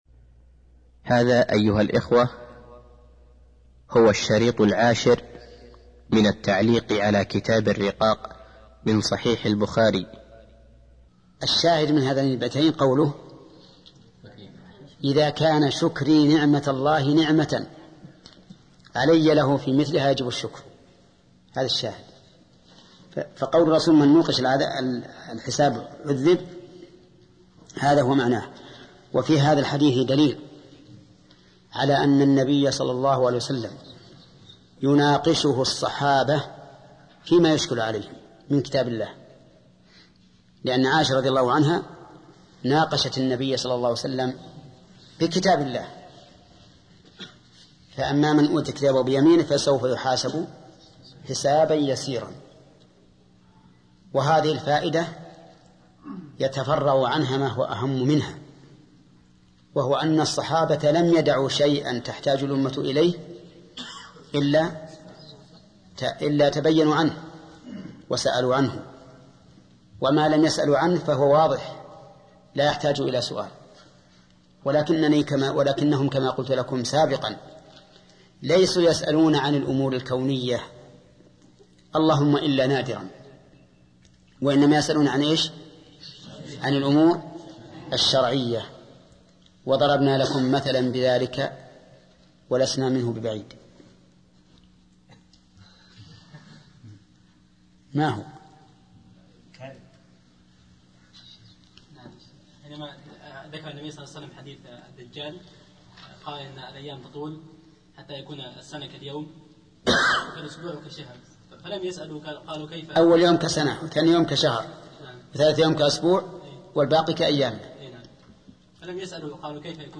الدرس العاشر -التعليق على كتاب الرقاق و القدر من صحيح البخاري - فضيلة الشيخ محمد بن صالح العثيمين رحمه الله